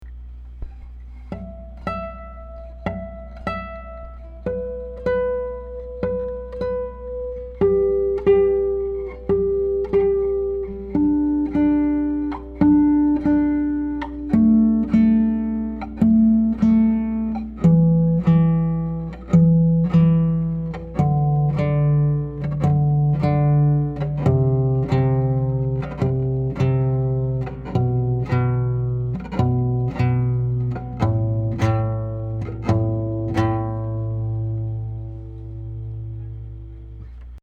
I have the guitar in D'Addario strings, tuned in Romantic tuning, a standard 10-string tuning where 1-6 is normal, and 7-10 descend step-wise: 7=D, 8=C, 9=B, 10 =A. Strings By Mail has a great selection of 10-string sets and can put together custom sets for you at a discount over individual strings.
Here are 14 quick, 1-take MP3 sound files of me playing this guitar, to give you an idea of what to expect. The guitar has amazing sympathetic resonance and sustain, as well as good power and projection, beautiful bass responce, and a very even response across the registers. These MP3 files have no compression, EQ or reverb -- just straight signal, tracked through a Neumann KM84/83 condenser mic, into an Audient Black mic preamp, into a Sony PCM D1 flash recorder.
2 | Intonation Test (Harmonic + fretted note @ the 12th fret)
CG40KM84ABD1Intonation.mp3